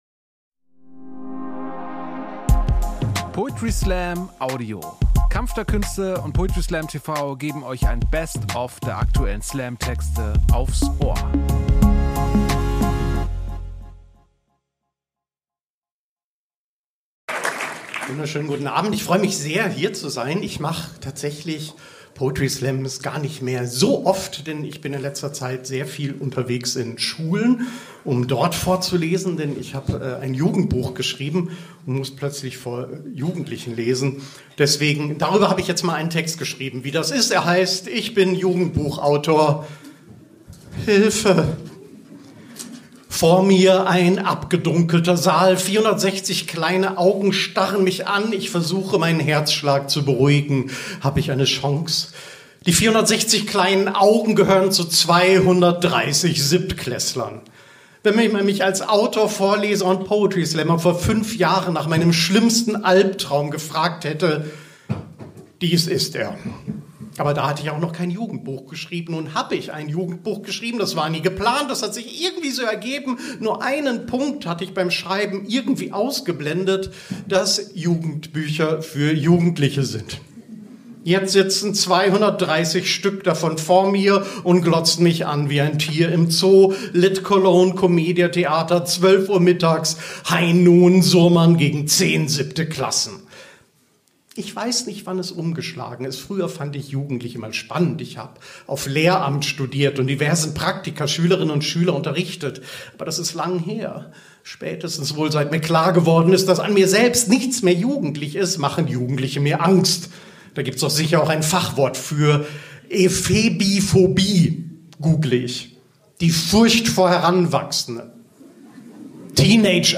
Kunst , Comedy , Gesellschaft & Kultur
Stage: Ernst Deutsch Theater, Hamburg